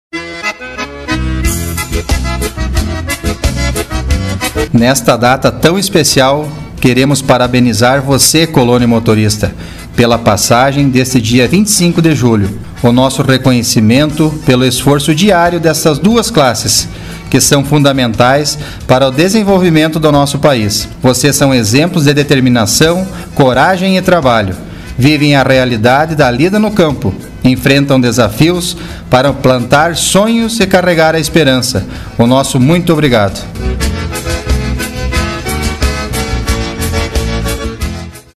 Mensagem do Prefeito Cleber Publicado em 17/09/18 Formato: audio/mpeg